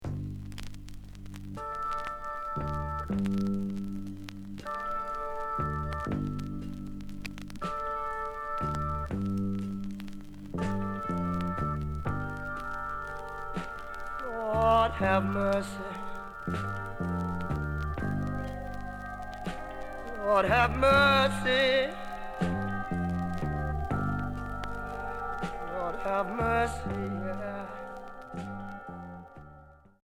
Expérimental